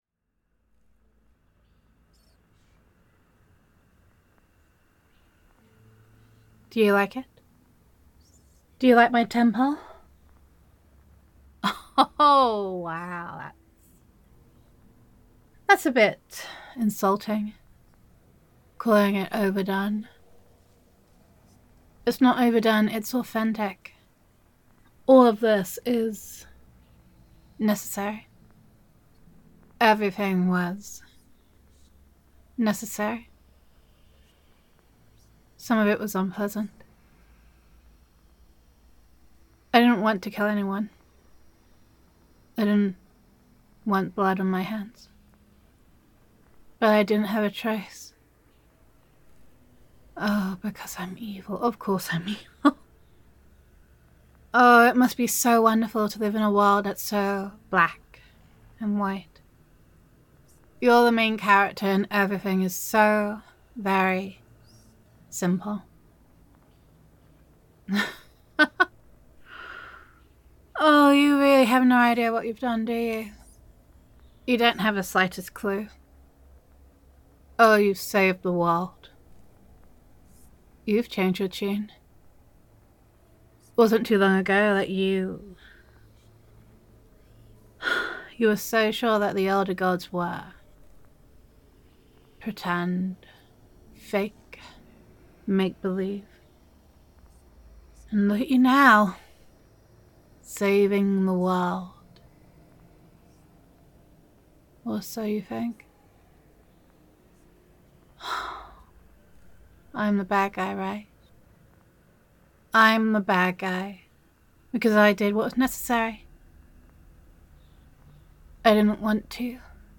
[F4A] Shades of Grey [Supernatural][Lovecraftian][Elder Gods][Cult Leader Roleplay][Gender Neutral][You Stopped an Evil Cult From Completing a Ritual to Awaken a Slumbering Elder God, or Maybe Not]